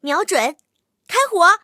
M22蝉开火语音2.OGG